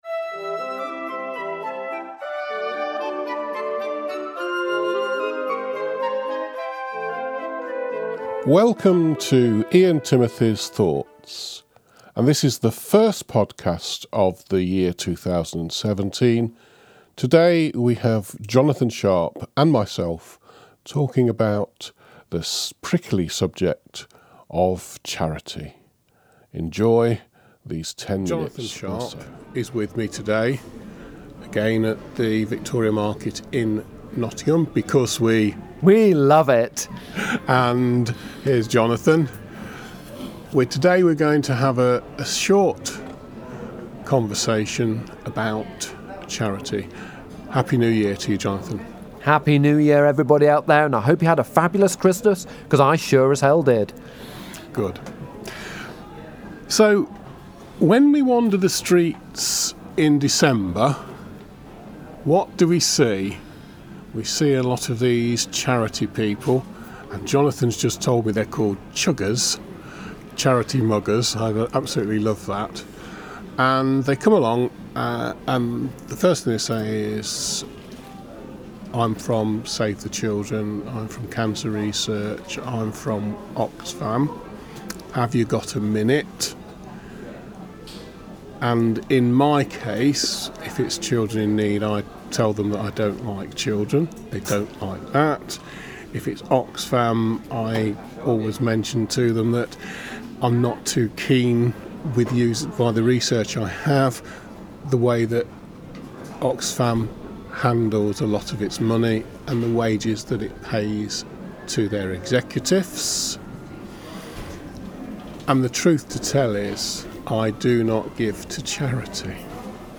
The podcast lasts for thirteen minutes and was recorded in the Victoria Market Nottingham.